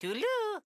Mario at the goal in Super Mario Bros. Wonder.
Mario_-_Toodleloo_-_SMBW.oga.mp3